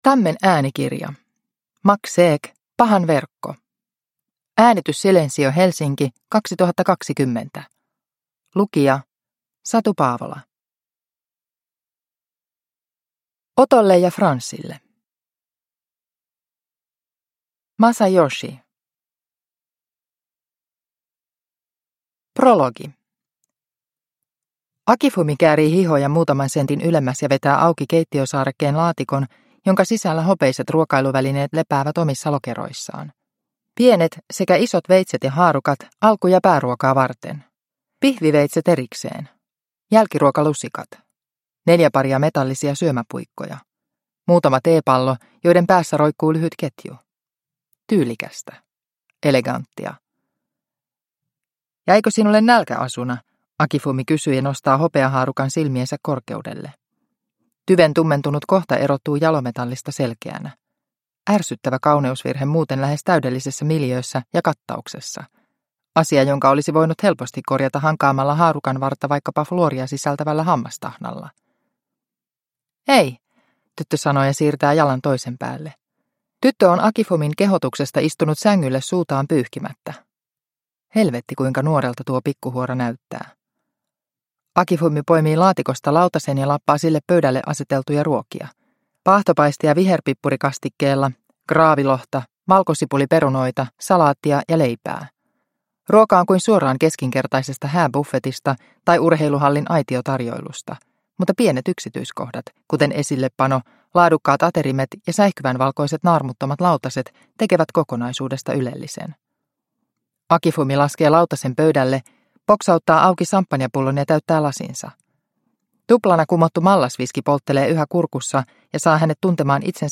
Pahan verkko – Ljudbok – Laddas ner